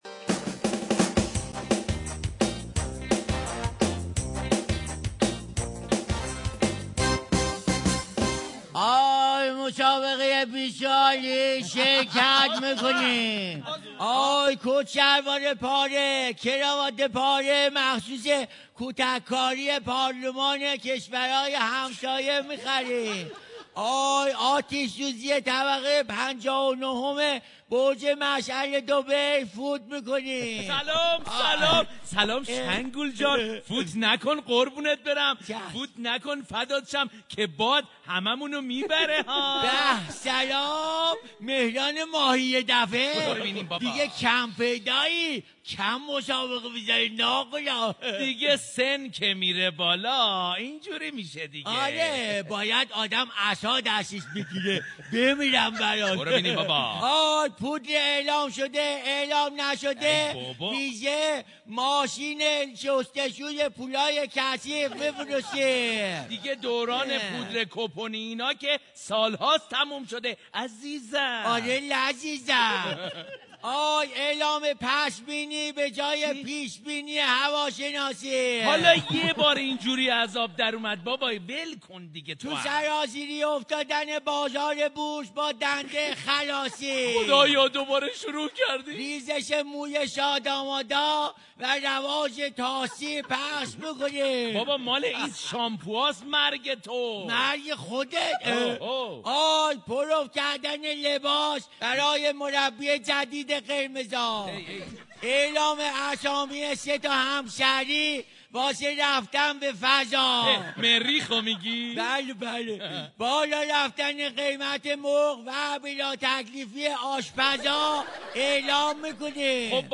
برنامه طنز رادیو ایران